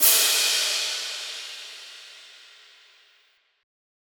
sizzle crash.wav